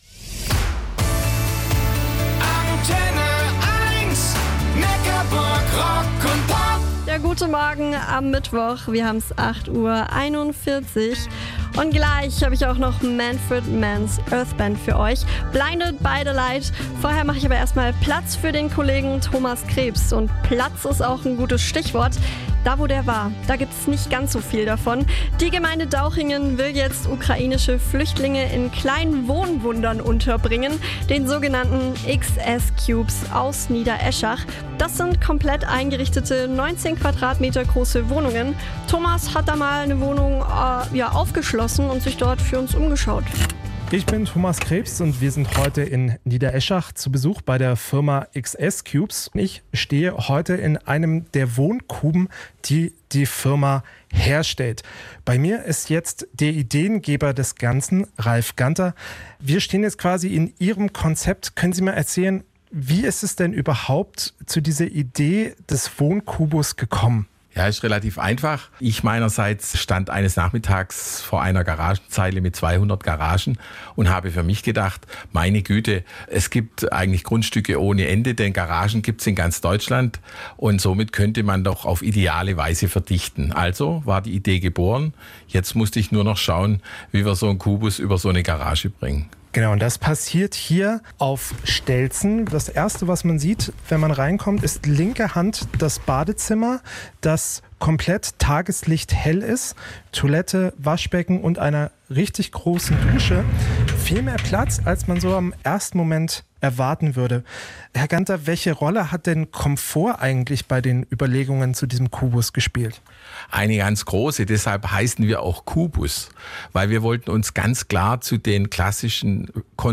INTERVIEW ANTENNE1/RADIO NECKARBURG